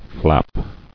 [flap]